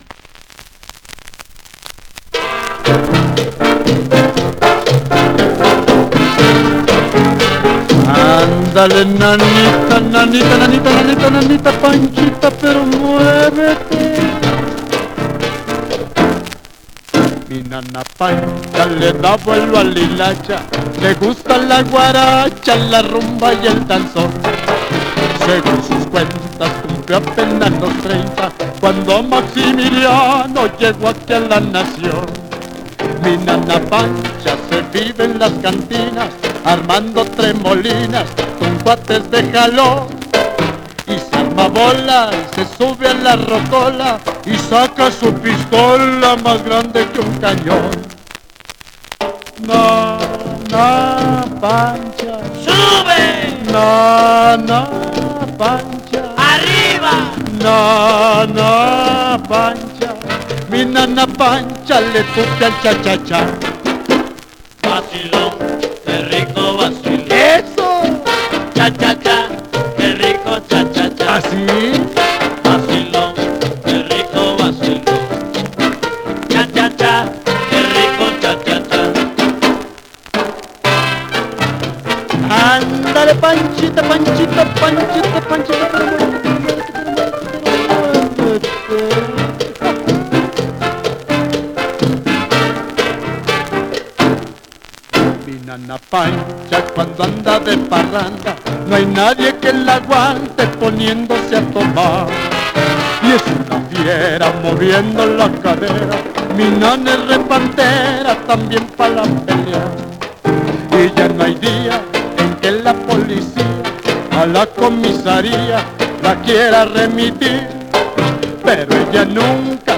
1 disco : 78 rpm ; 25 cm